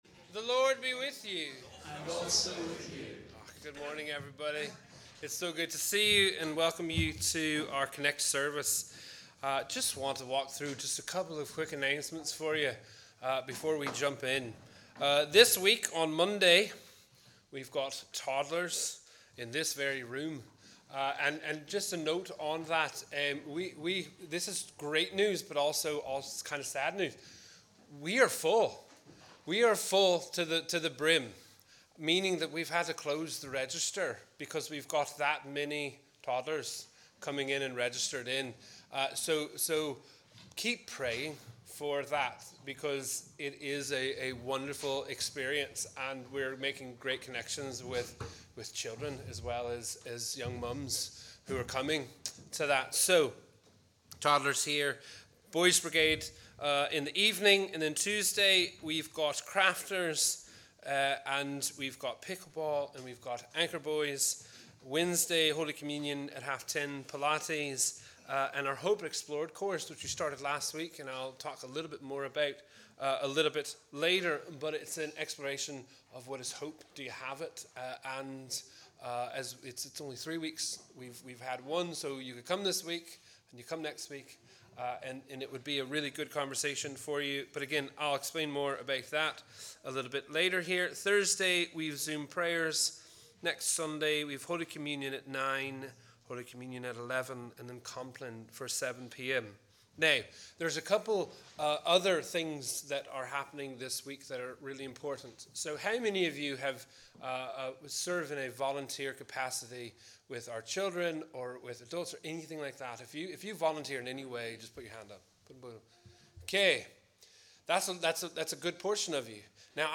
We warmly welcome you to our CONNEC+ service as we worship together on the 4th Sunday after the Epiphany.